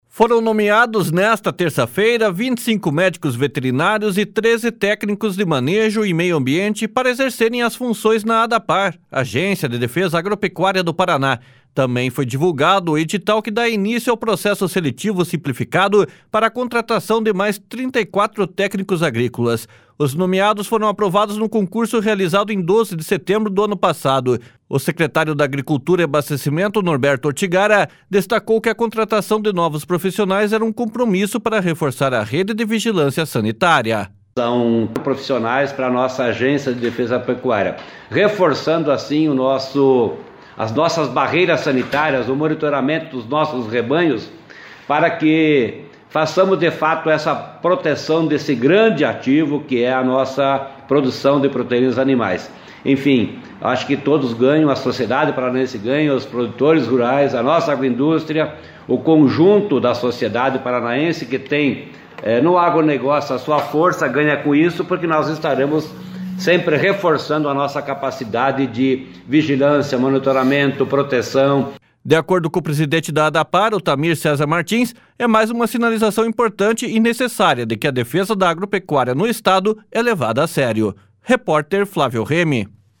//SONORA NORBERTO ORTIGARA//